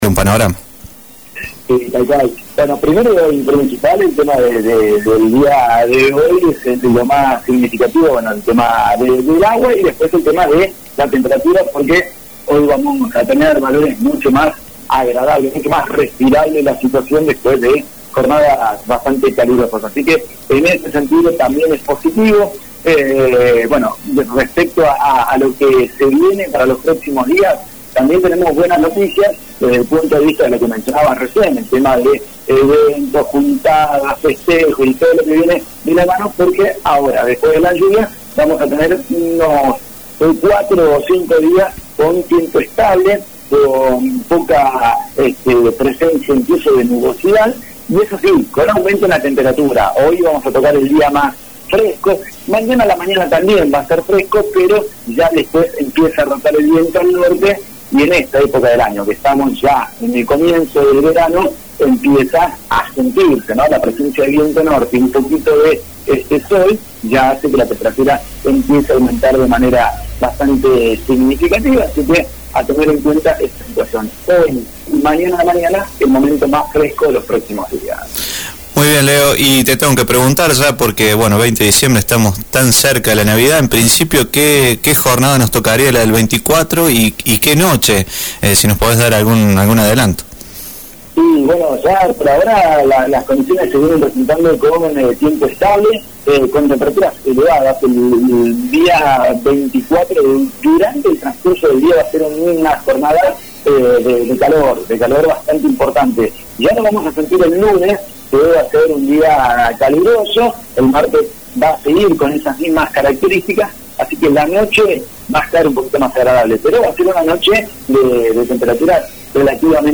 el pronóstico del tiempo para la jornada de hoy